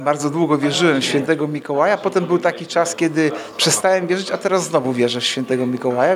To już piękna świąteczna tradycja – wigilia w Radiu 5 Ełk.
W święta przychodzi do nas Mikołaj. Wie o tym Artur Urbański, zastępca prezydenta Ełku.